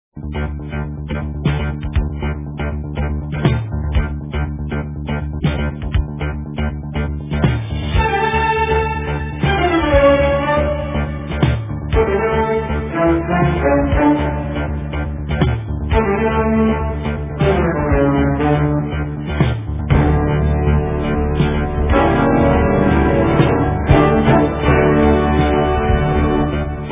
в современной обработке
оркестра